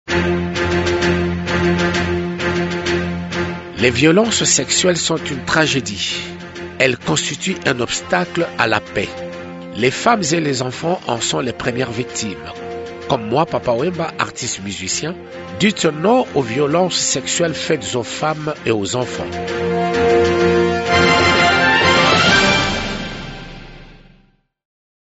Ecoutez ici les messages de Papa Wemba, chanteur et leader d’opinion congolais, à l’occasion de la campagne de lutte contre les violences faites aux femmes et aux enfants: